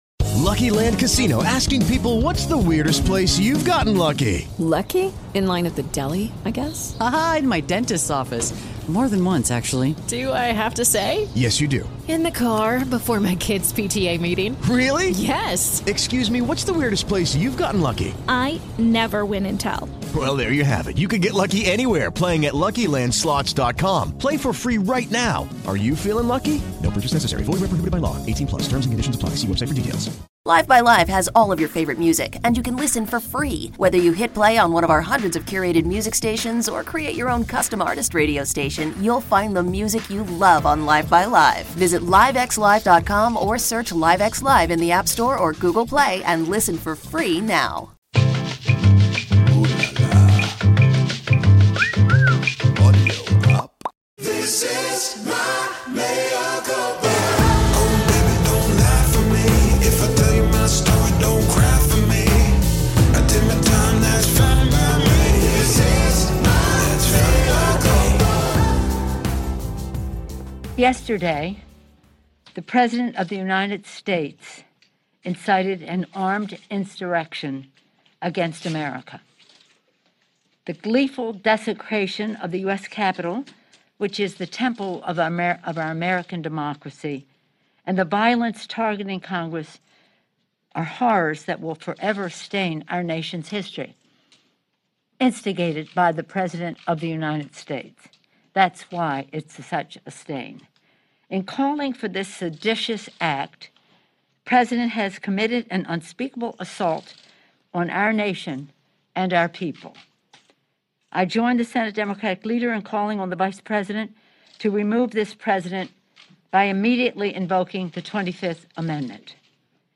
BEST OF MEA CULPA: EXCLUSIVE INTERVIEW WITH BEN STILLER ON PLAYING MICHAEL COHEN